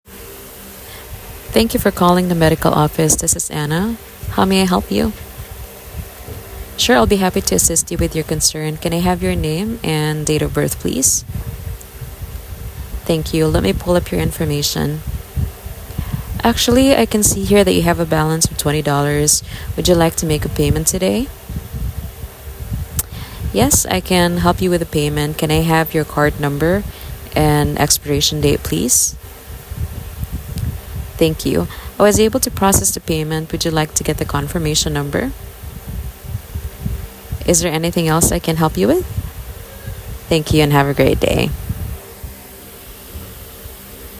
Patient Call Center Example
Global conducts extensive dialect training for all patient call center employees. Click the button to hear a sample call: